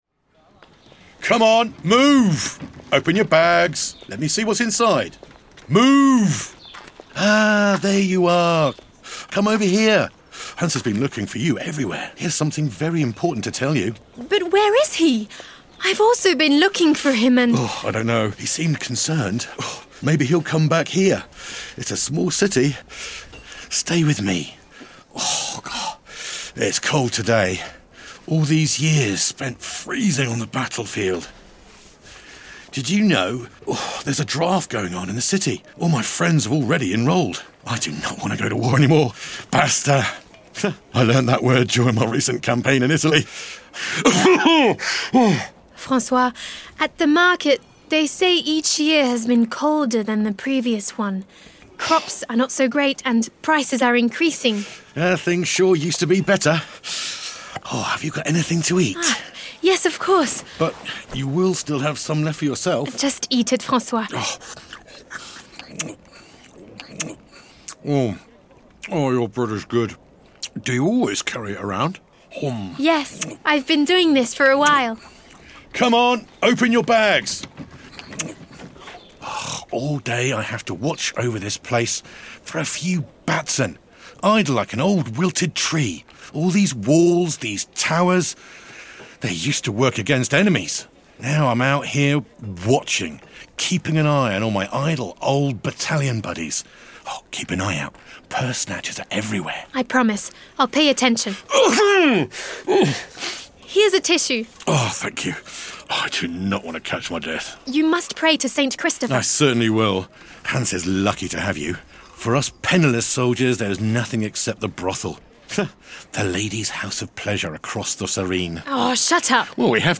Engels (Brits)
Diep, Opvallend, Volwassen, Warm, Zakelijk